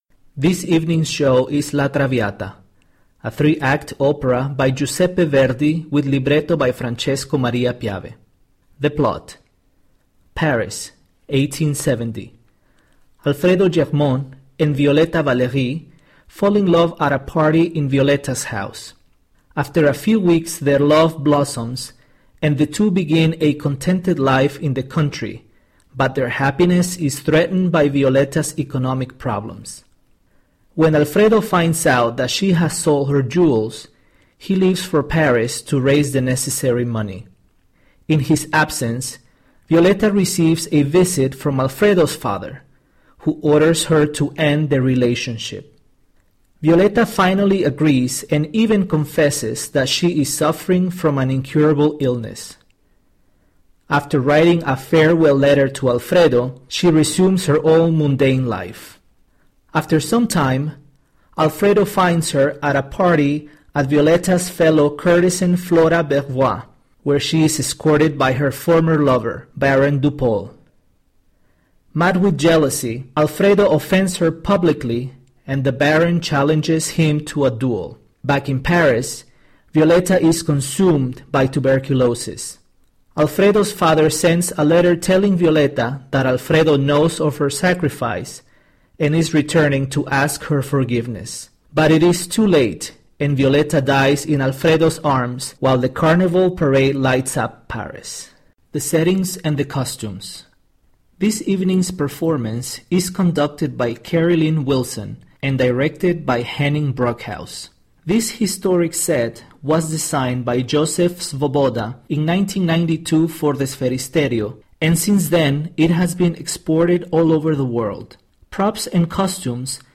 InclusivOpera: Audio Description
Audio description is a verbal commentary for the blind and visually impaired audience, with the aim to increase the accessibility of the opera performance by providing a detailed synopsis of the work, a tailored description of the set design, the costumes, and of what is happening visually on stage. Our audio descriptions also include details provided by the artists themselves (director, costume designer, light designer, set designer, and many others), in order to highlight the style of and the ‘philosophy’ behind the production.